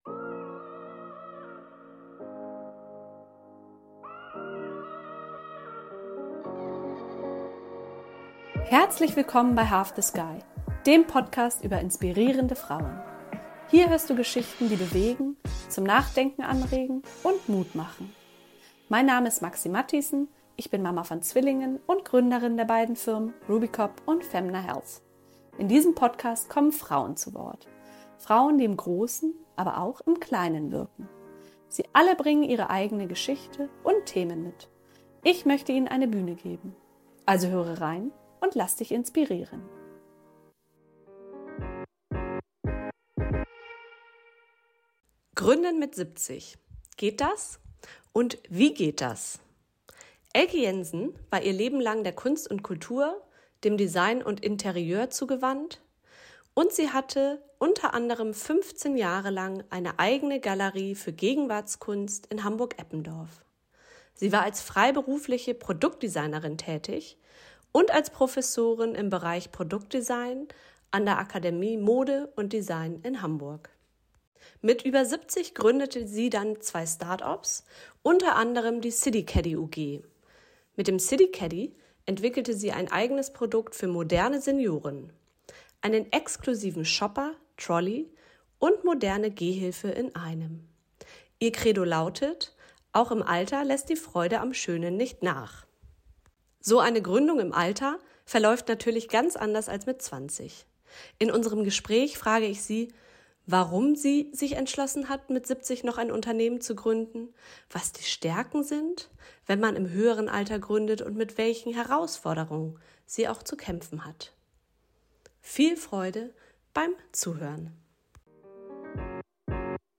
So eine Gründung im Alter verläuft natürlich ganz anders als mit 20 - in unserem Gespräch frage ich sie, warum sie, warum sie sich entschlossen hat, mit 70 ein Unternehmen zu gründen, was die Stärken sind, wenn man im höheren Alter gründet und mit welchen Herausforderungen sie kämpft.